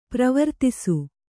♪ pravartisi